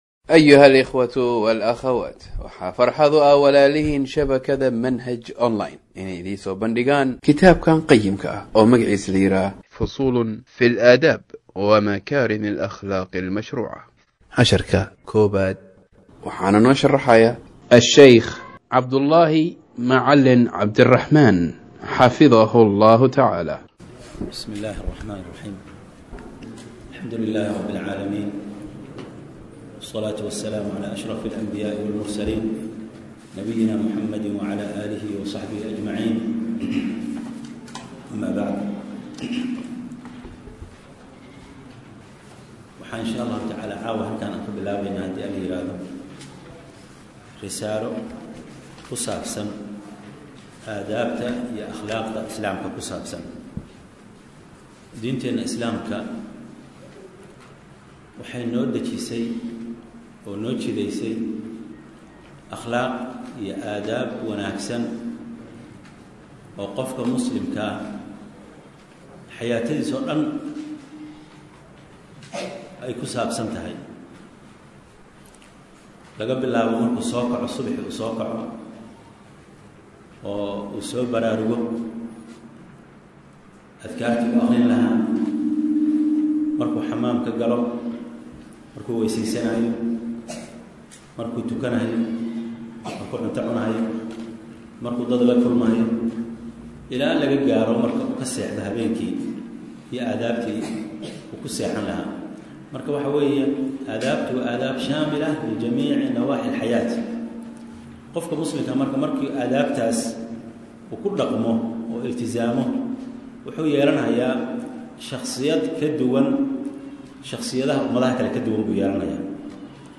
Fusuul Fii Al-Aadaab Wa Makaarim Al-Akhlaaq - Darsiga 1aad - Manhaj Online |